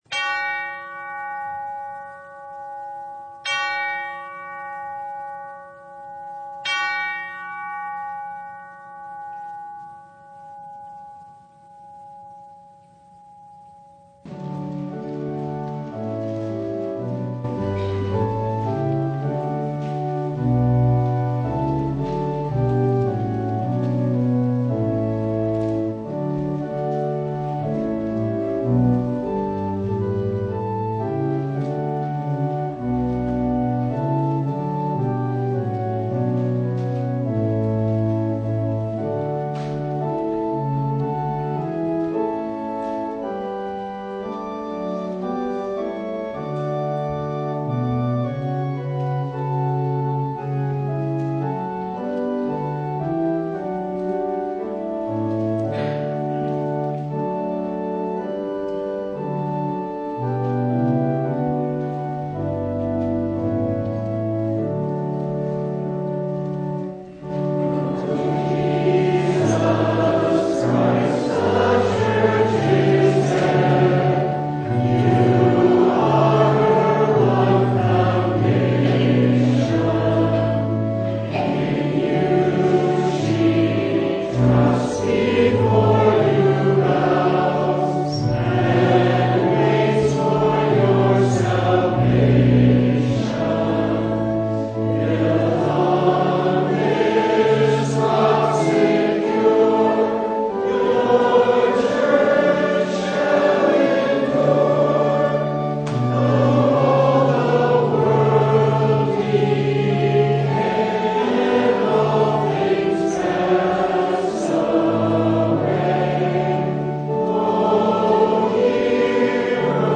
Download Files Bulletin Topics: Full Service « We Preach Christ Crucified Living and Dying—For What?